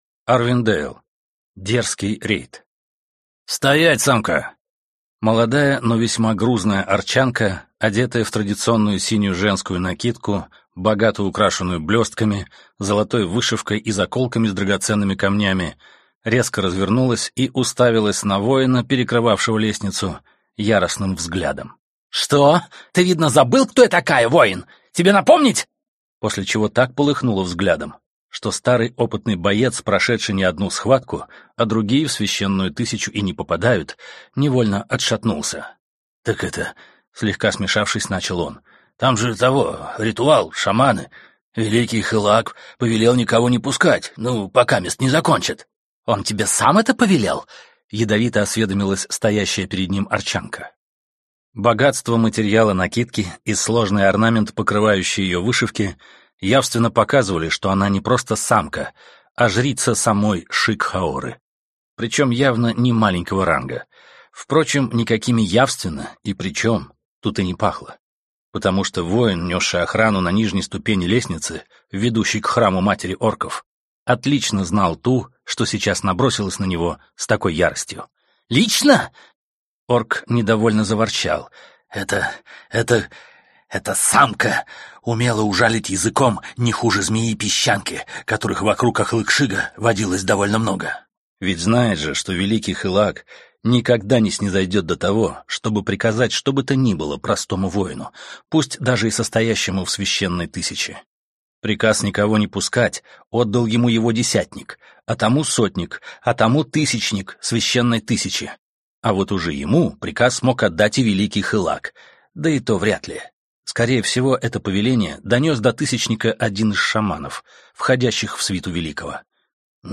Аудиокнига Дерзкий рейд | Библиотека аудиокниг